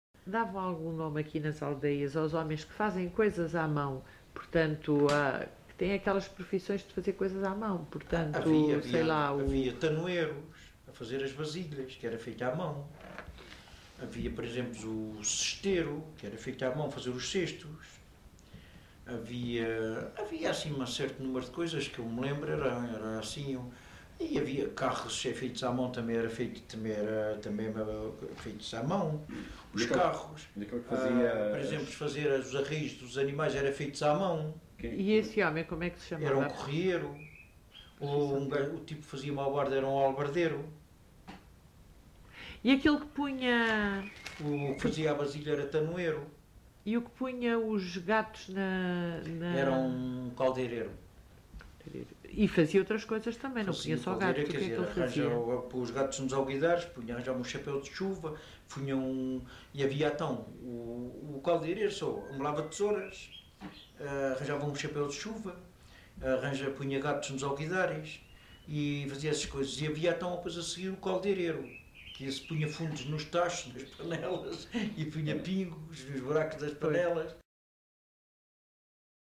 LocalidadeEnxara do Bispo (Mafra, Lisboa)